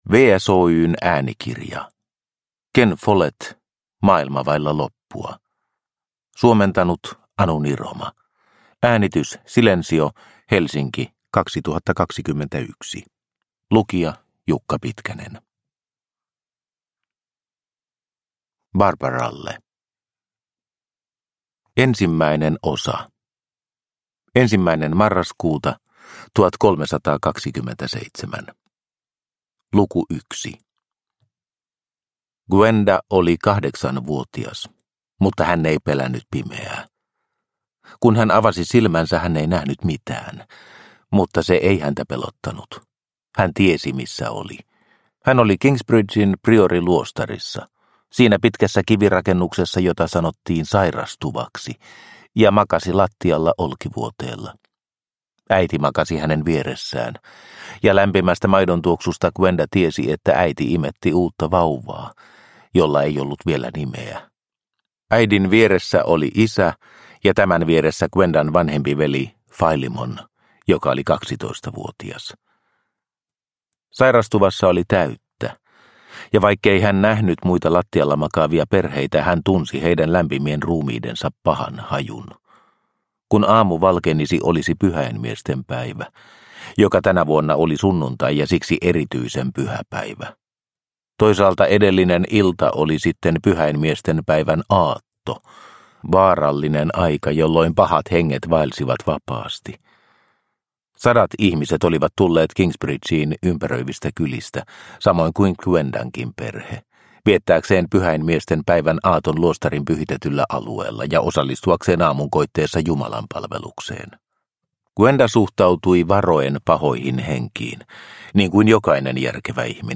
Maailma vailla loppua – Ljudbok – Laddas ner